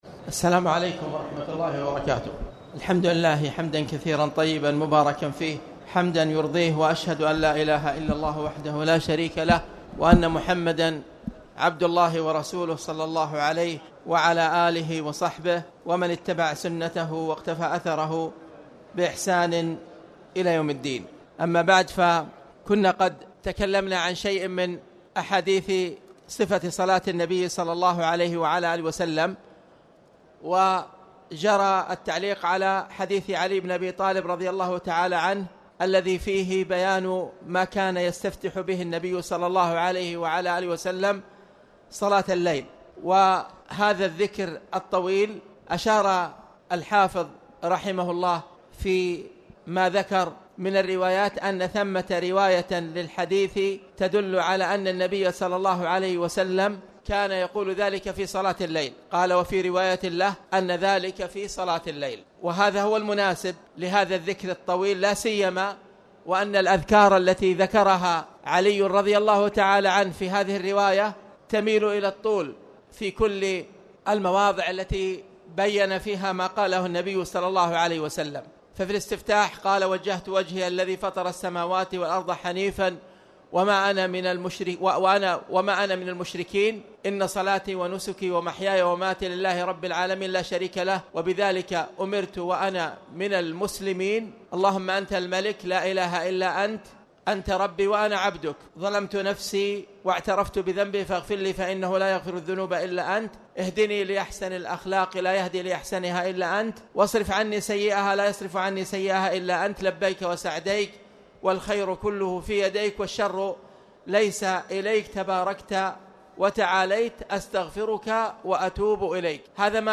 تاريخ النشر ٥ رمضان ١٤٣٨ هـ المكان: المسجد الحرام الشيخ